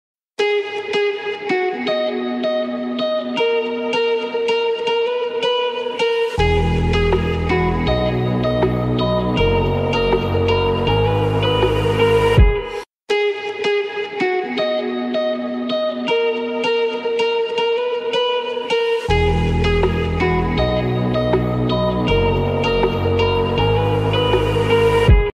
Instrumental Ringtone